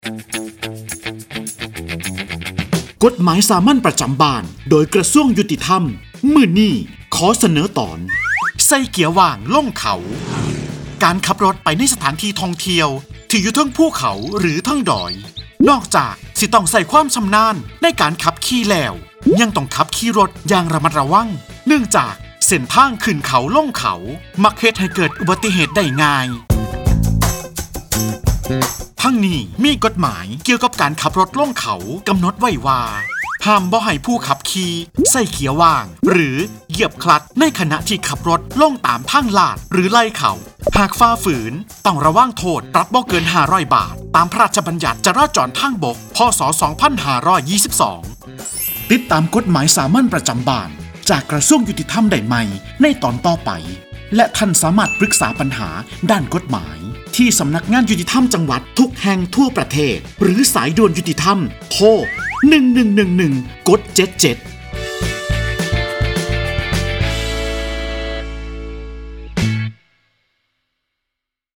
กฎหมายสามัญประจำบ้าน ฉบับภาษาท้องถิ่น ภาคอีสาน ตอนใส่เกียร์ว่างลงเขา
ลักษณะของสื่อ :   คลิปเสียง, บรรยาย